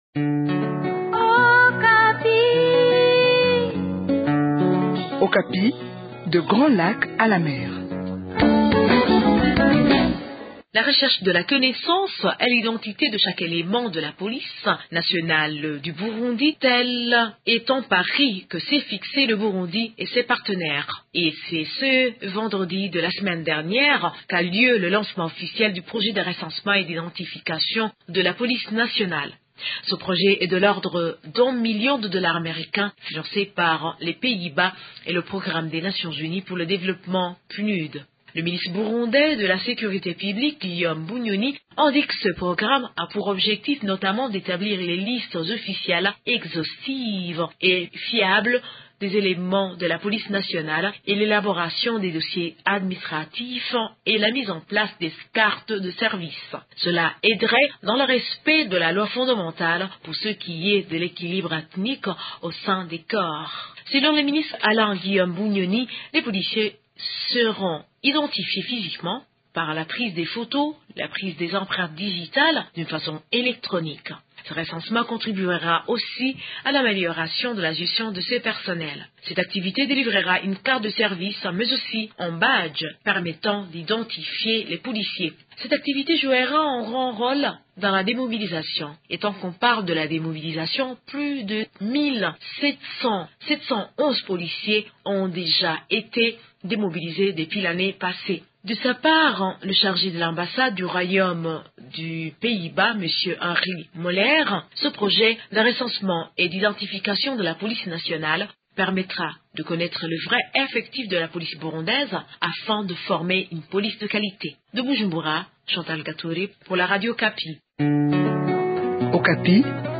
notre correspondante au Burundi nous apporte des précisions à ce sujet.